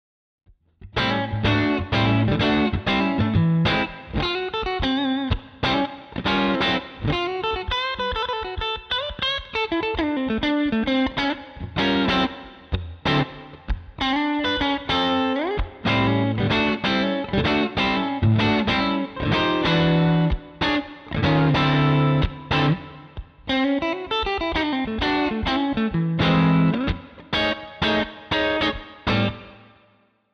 Für die Klangbeispiele wurde das Crazy Tube Circuits Crossfire ohne Amp (DI) in Verbindung mit einer Boxensimulation sowie Hall verwendet.
Nur Amp-Sektion mit mehr Gain. Ohne Headroom und Bright Schalter.